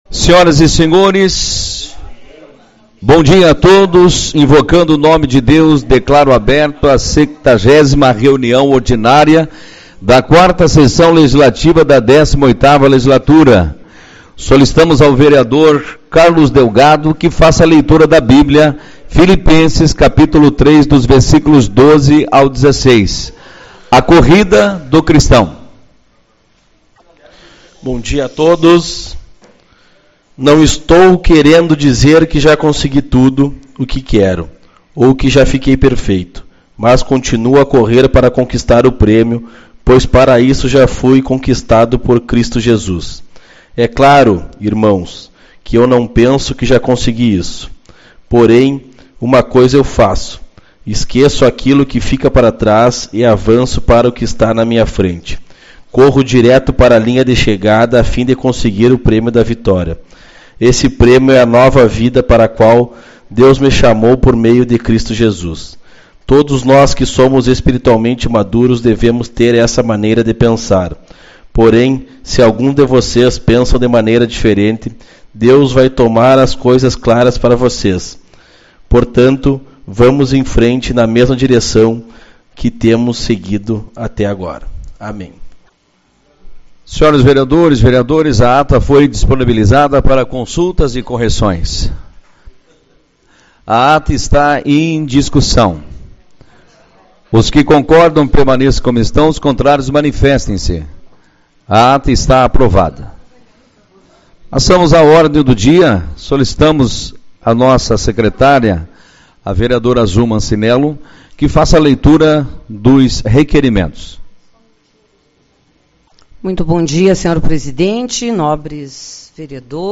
29/10 - Reunião Ordinária